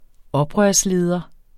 Udtale [ ˈʌbʁɶɐ̯s- ]